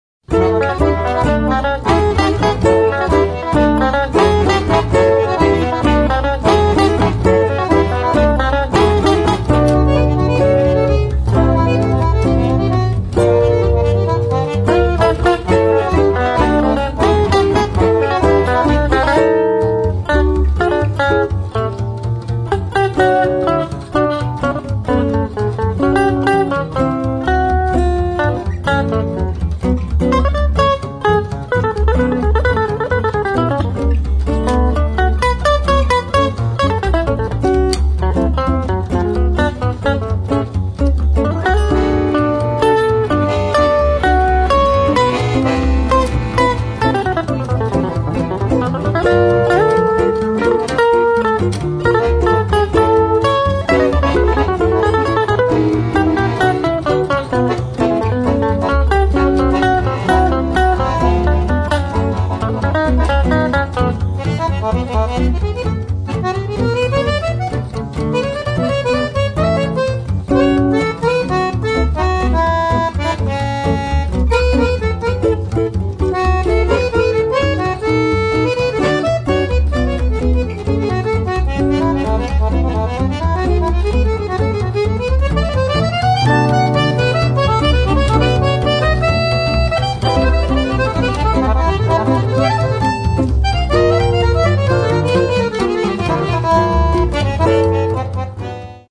– Un quartet de jazz de plus !
– Yes, but there’s an accordion.
– And a double bass.
– And tender.
– No, it’s quite lively.